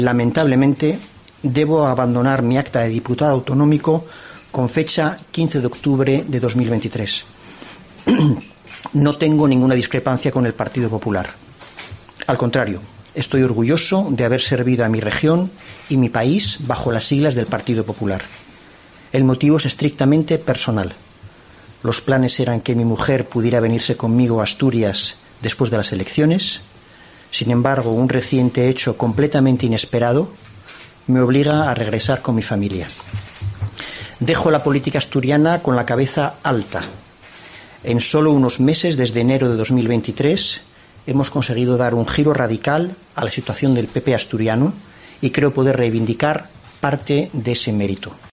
Diego Canga explica las razones de su dimisión
En una comparecencia sin preguntas en la sede de la Junta General del Principado, Canga ha leído la carta que ha remitido al secretario general del PP asturiano, Álvaro Queipo, en la que le comunica su renuncia al escaño por un "hecho inesperado" que le obliga a regresar junto a su familia a Bruselas, donde hasta el pasado mes de diciembre trabajaba como alto funcionario de la Comisión Europea.